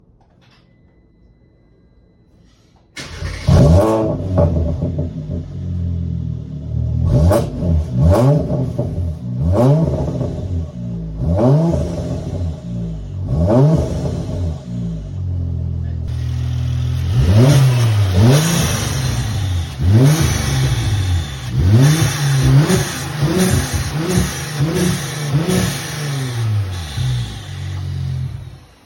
Previously Mp3 Sound Effect New flow master fx. Previously had muffler delete and resonator delete . These sound great with no resonator.